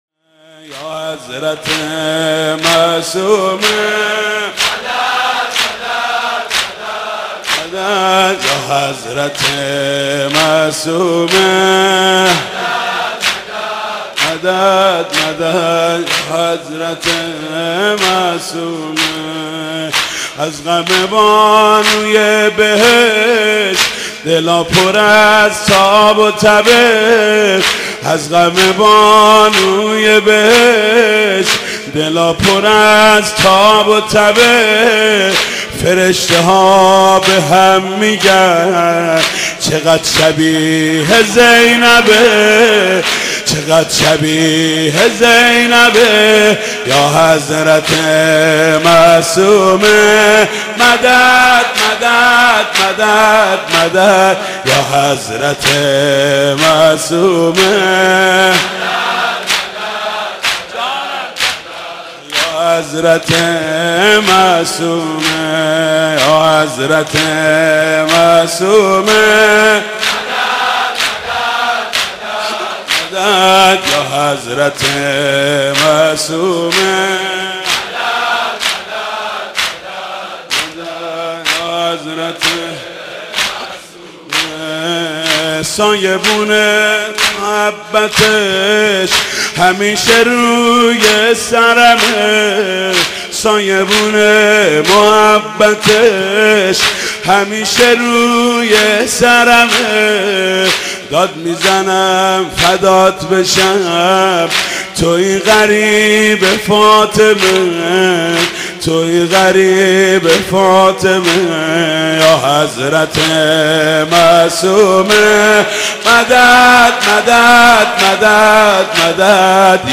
ویژه شهادت حضرت معصومه (زمینه)